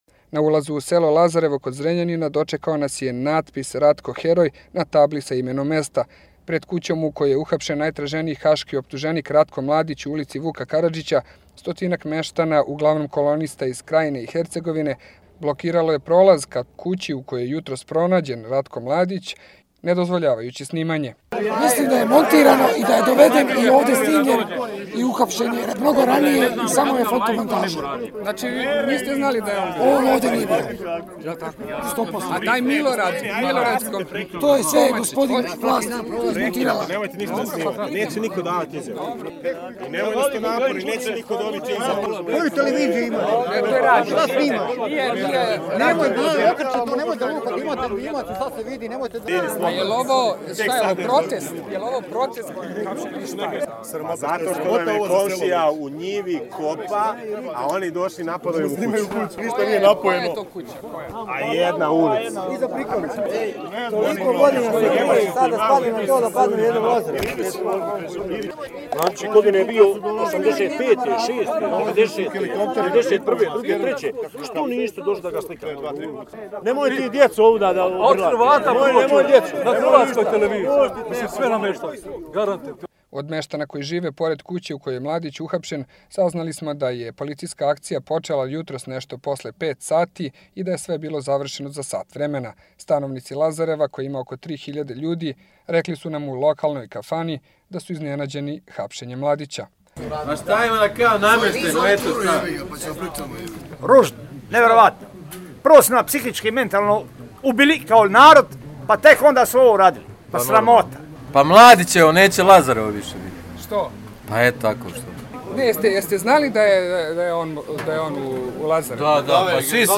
Reportaža
iz Lazareva nakon hapšenja Mladića